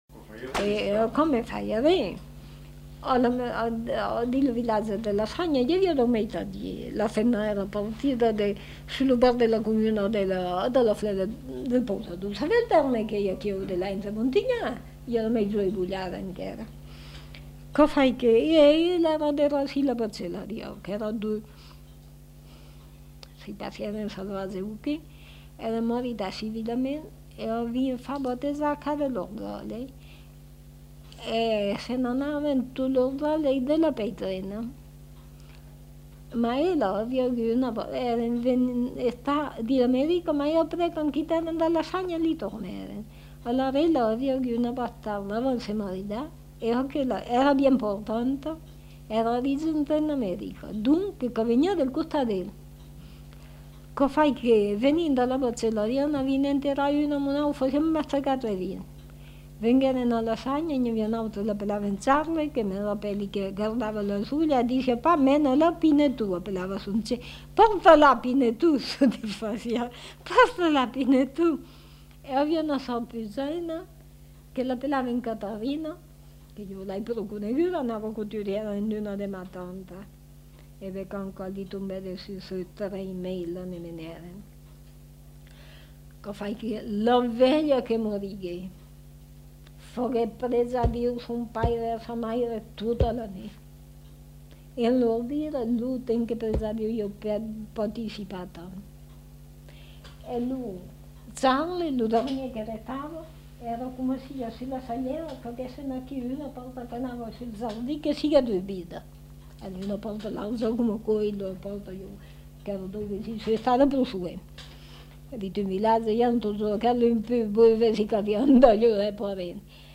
Aire culturelle : Périgord
Lieu : La Chapelle-Aubareil
Genre : conte-légende-récit
Type de voix : voix de femme
Production du son : parlé
Classification : récit de peur